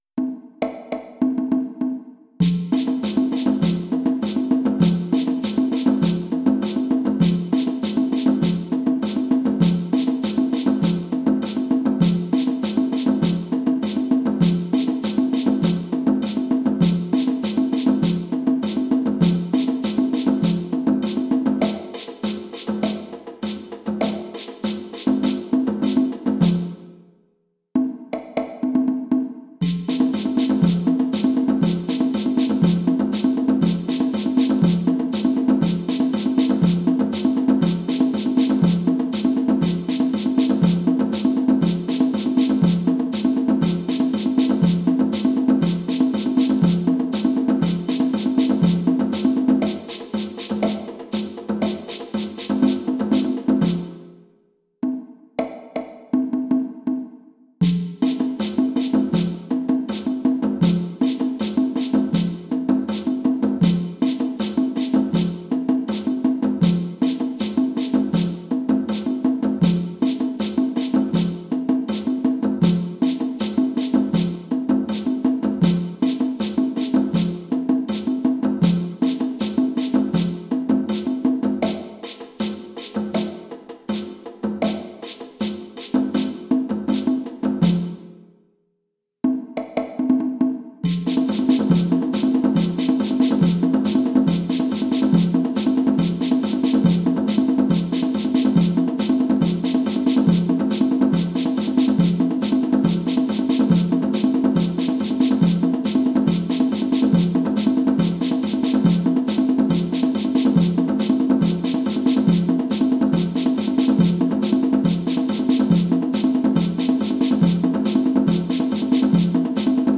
Full performance (audio) [low drum doubles Lead drum]